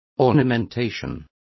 Complete with pronunciation of the translation of ornamentation.